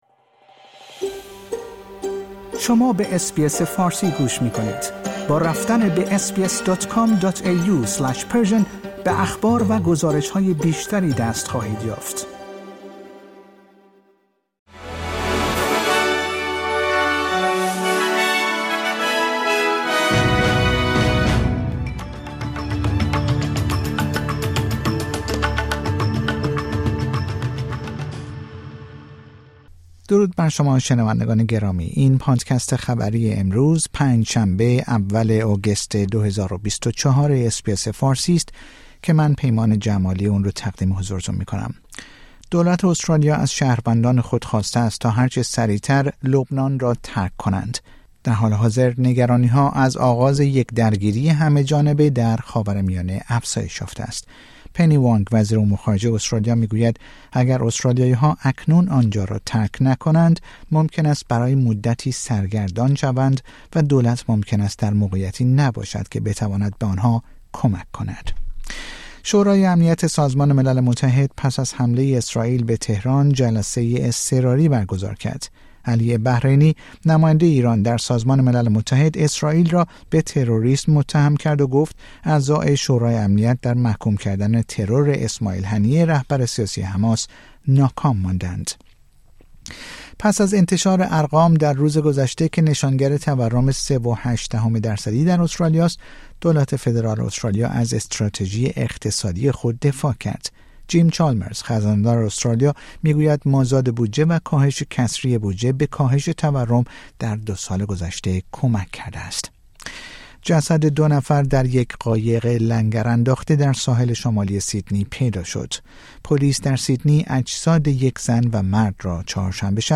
در این پادکست خبری مهمترین اخبار استرالیا در روز پنج شنبه اول آگوست ۲۰۲۴ ارائه شده است.